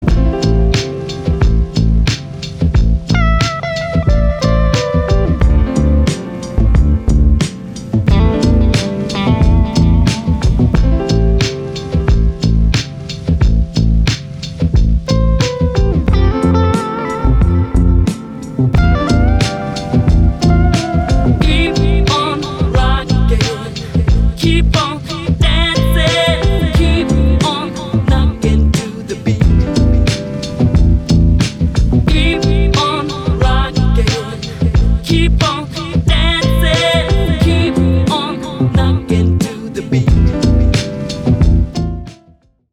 • Качество: 320, Stereo
ритмичные
Electronic
спокойные
Downtempo
Стиль: lounge, chillout